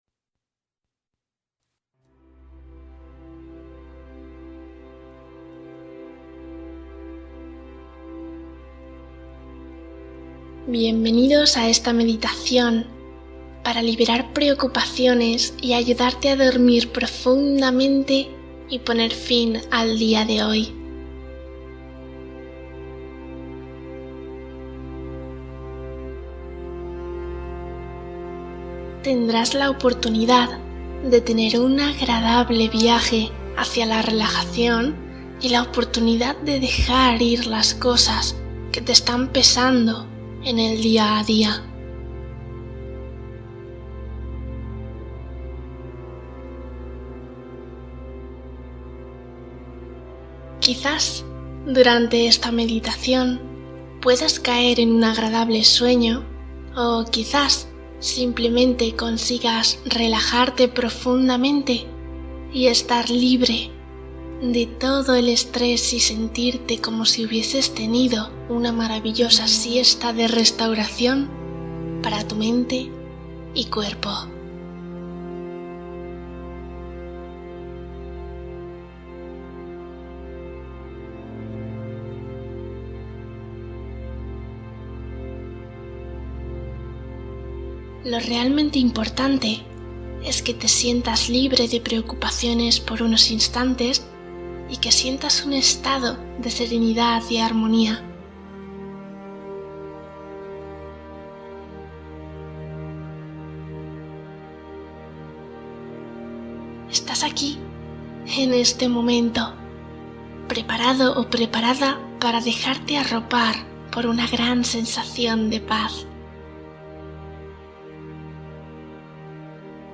Hipnosis para dormir profundamente | Libera preocupaciones